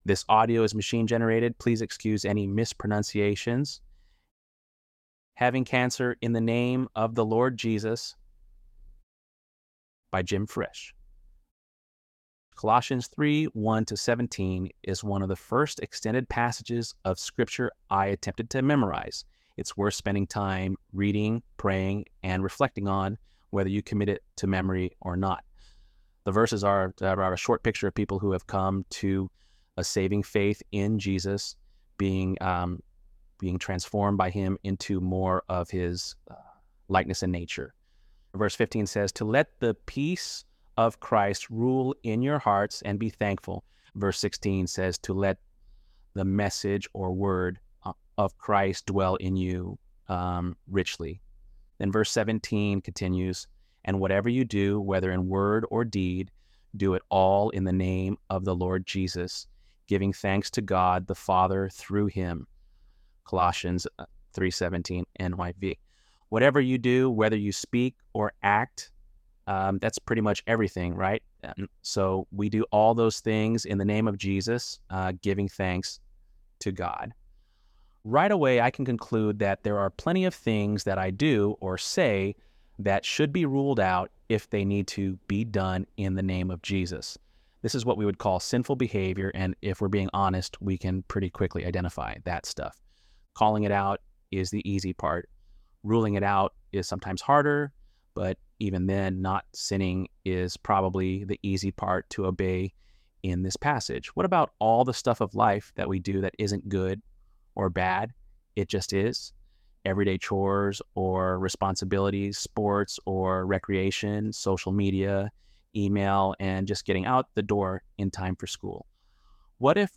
ElevenLabs_2_13.mp3